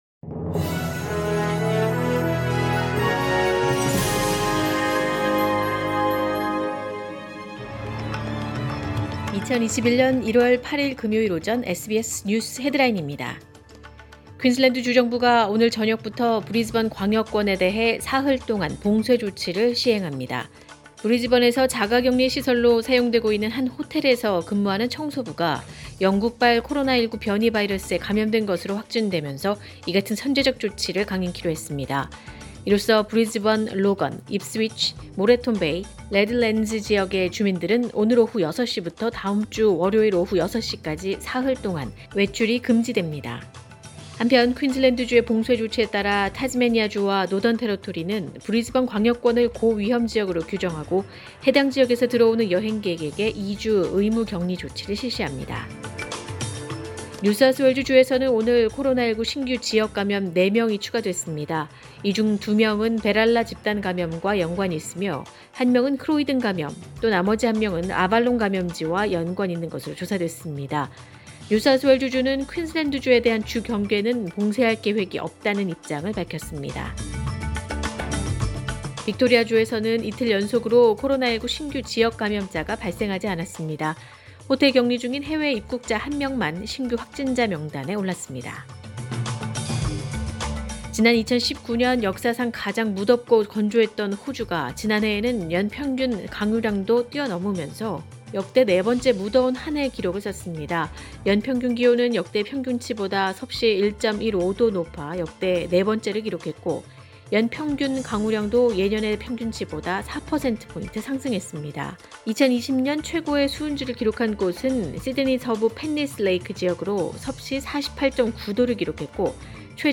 2021년 1월 8일 금요일 오전의 SBS 뉴스 헤드라인입니다.